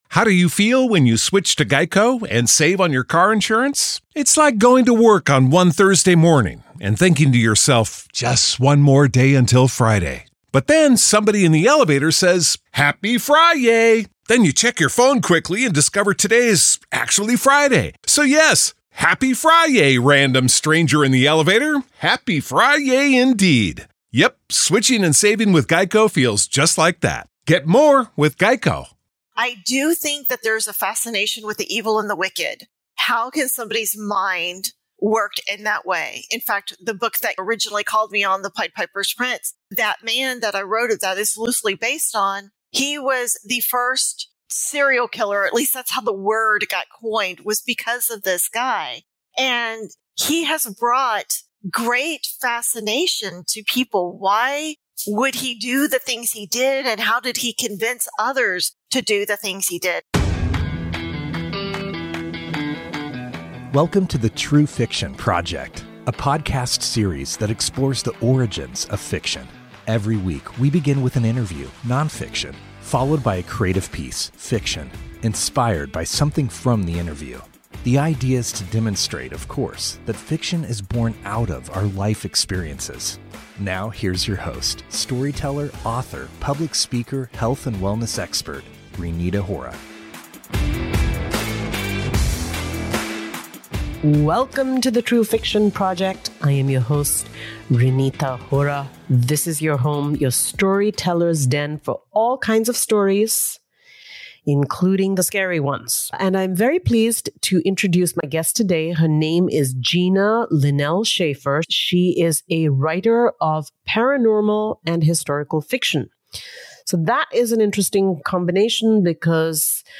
[18:57] We hear an excerpt from the Pied Piper’s Prince.